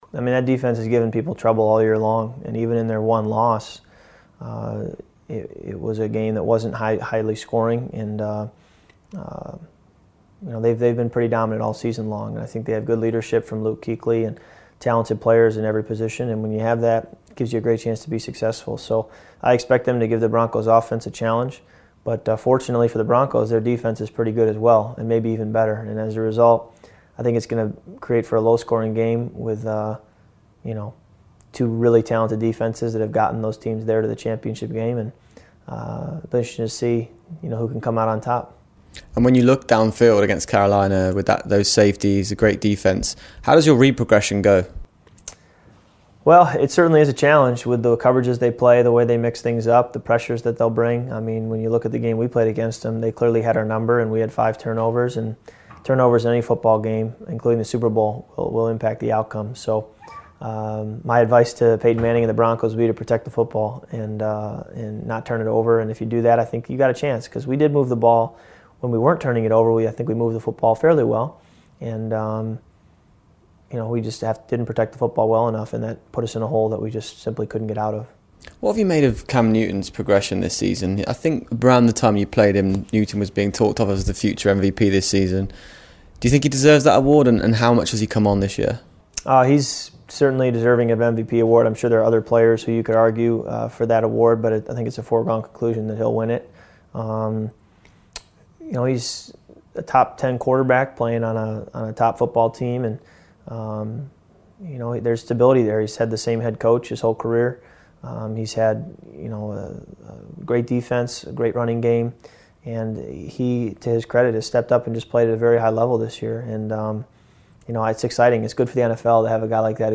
Hear the full interview on tomorrow's Gridiron Show.